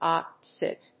/άpsít(米国英語), ˈɔpsít(英国英語)/